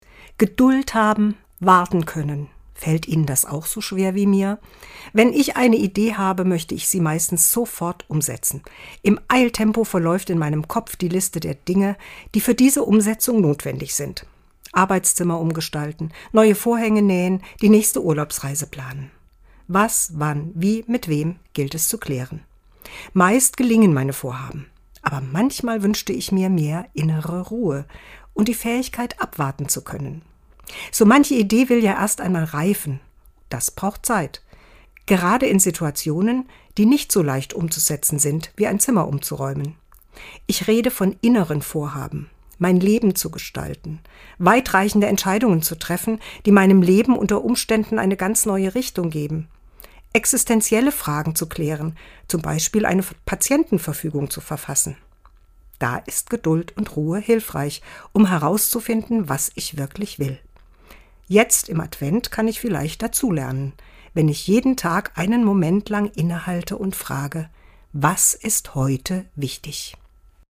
Dezember 2024, Autorin und Sprecherin ist